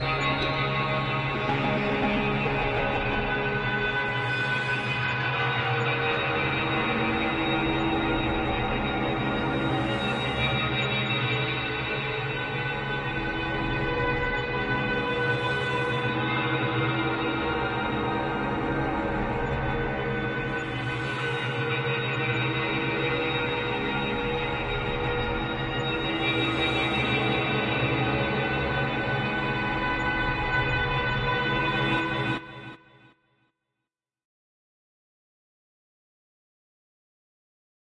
描述：带有回声层的黑暗环境无人机。
Tag: 环境 回声 无人驾驶飞机